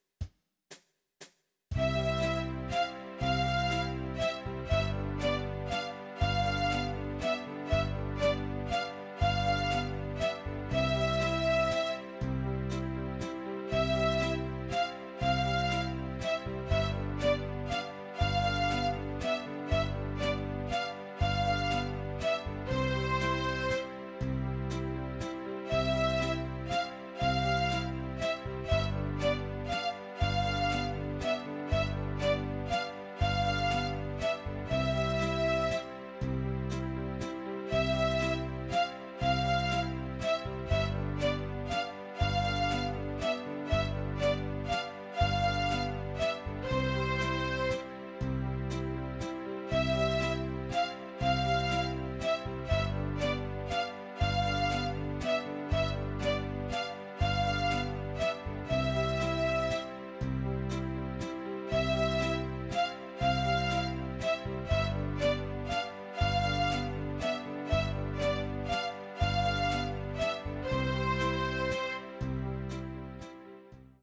Poco convencido compré un teclado usado y el libro Keyboard Starter 1.